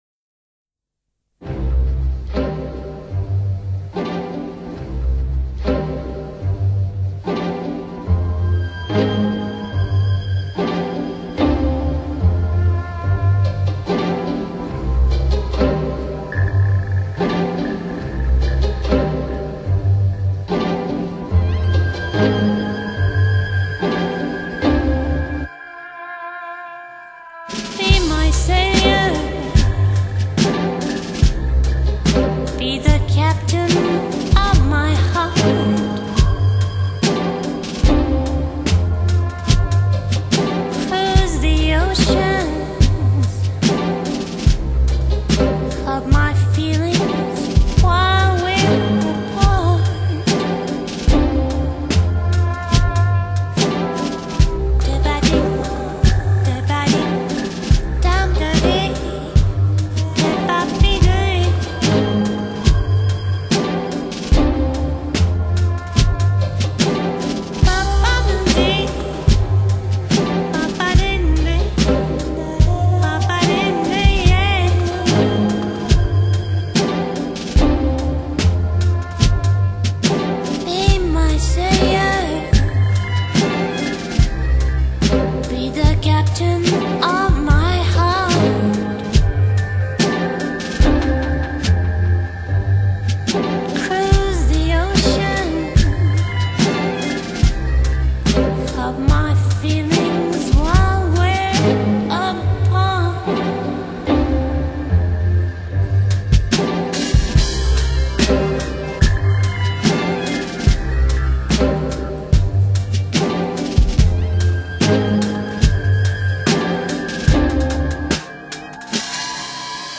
Electronic, Pop, Downtempo, Acid Jazz, Trip Hop